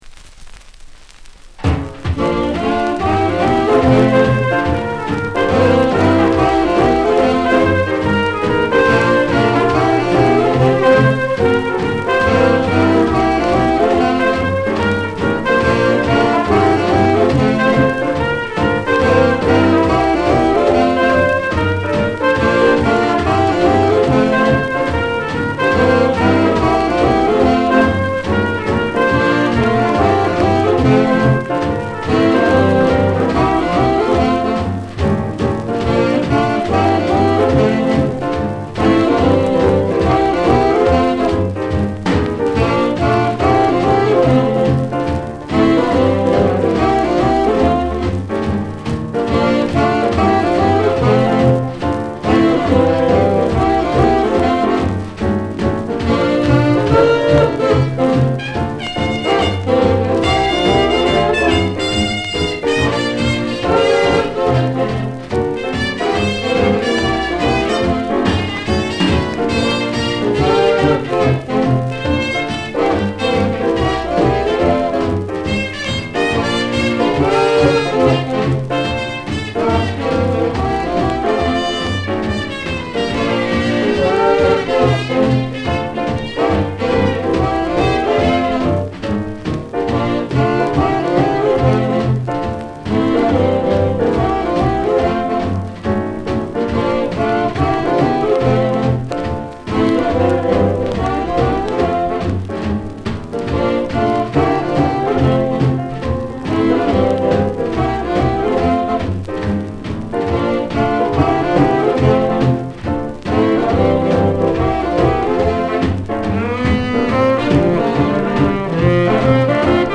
recorded 1954
78 rpm
mono
tenor sax & leader
trumpet
piano
bass
drums
alto sax